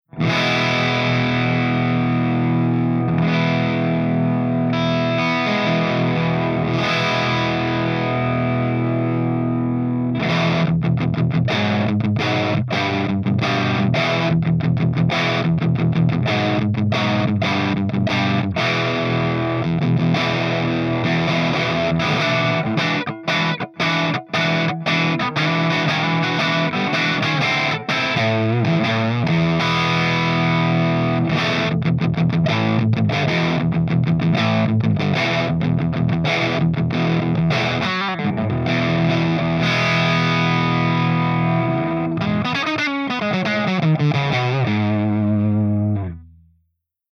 110_PLEXI_CH2EXTRADRIVE_GB_P90